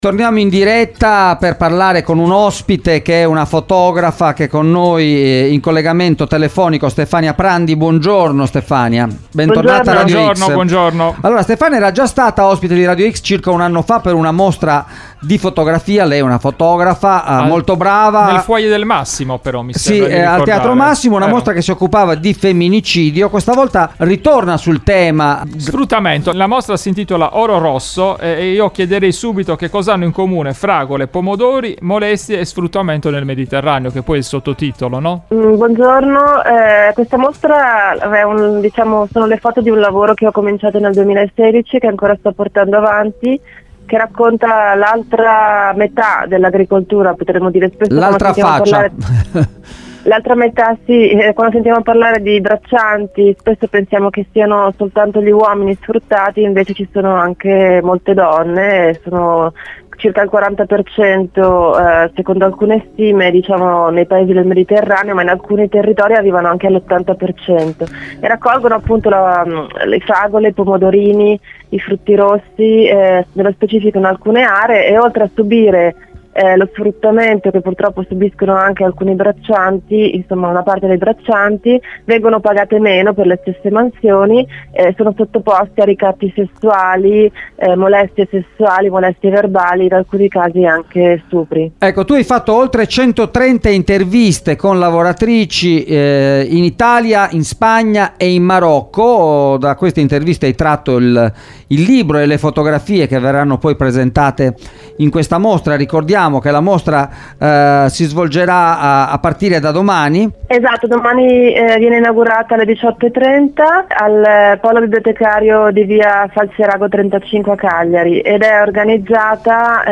A Cagliari il dramma delle braccianti nella mostra “Oro rosso” – Intervista
in collegamento telefonico